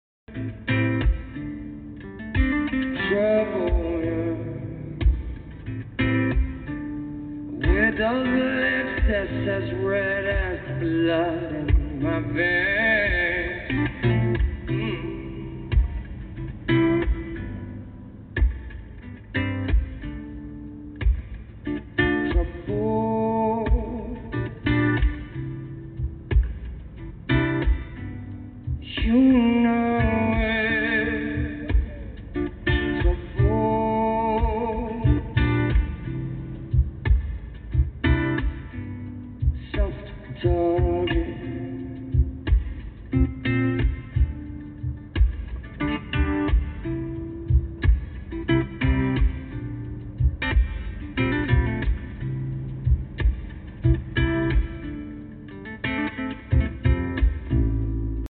Audio with Amplifier after TUNING. sound effects free download